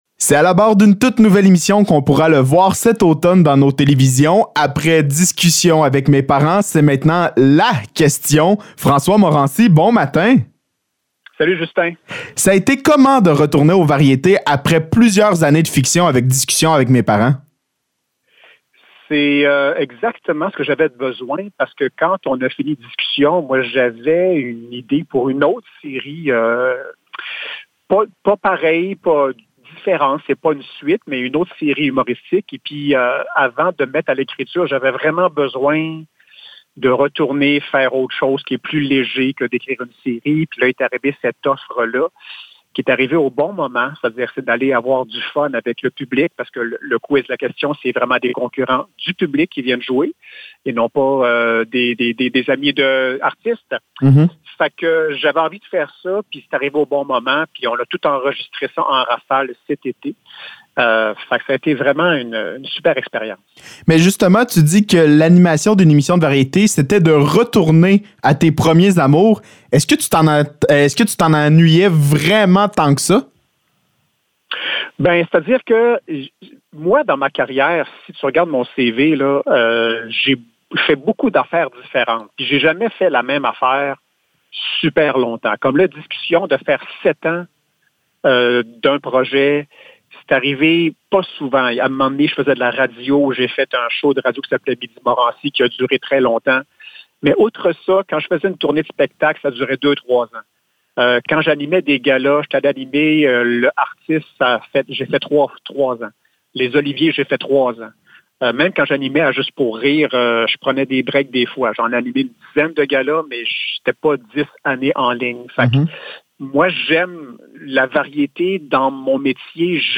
Entrevue avec François Morency